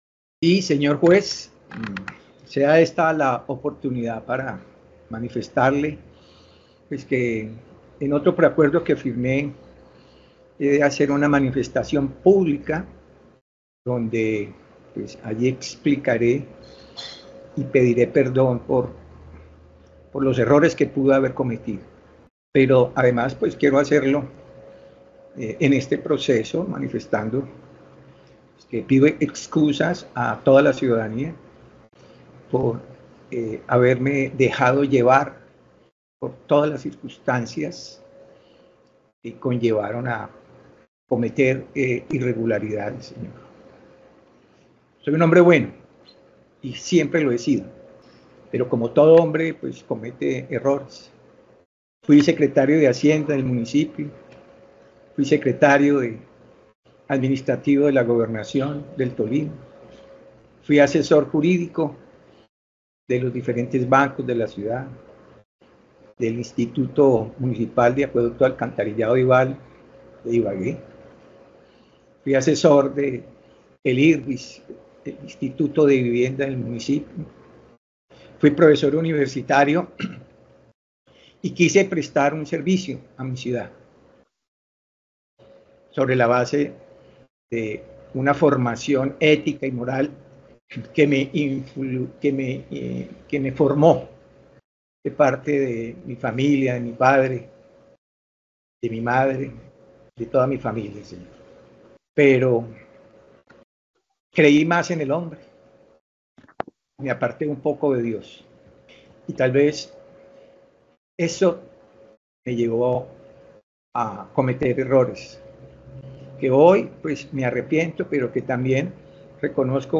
Durante la audiencia donde se conoció la condena, Luis H. Rodríguez ofreció excusas públicas por sus actuaciones y recalcó que desvió el camino en el manejo de los recursos públicos.
Asimismo el exmandatario en tono reflexivo dijo apartarse de Dios y creer en el hombre y al mismo tiempo señaló que su error fue depositar su confianza en una sola persona además que no realizó los controles debidos y los seguimientos de rigor.